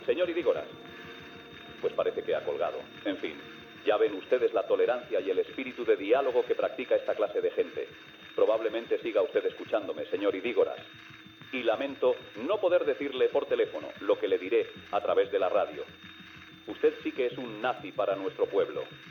Enfrontament dialèctic entre Luis del Olmo i el portaveu d'Herri Batasuna Jon Idígoras, després de l'assassinat del capità de Farmàcia Alberto Martín Barrios, el dia anterior (Havia estat segrestat per ETA el 5 d'octubre)
Info-entreteniment